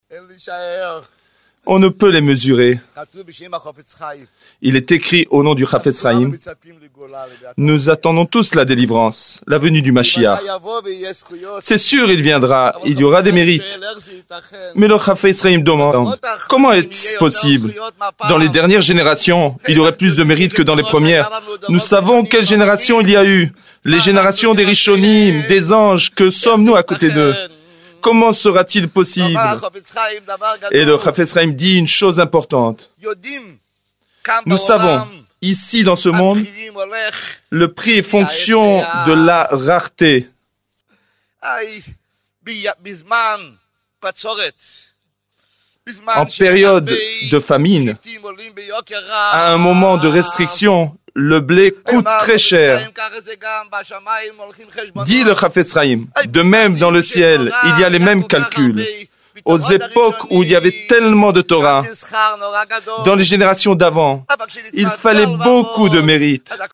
Traduction Simultnée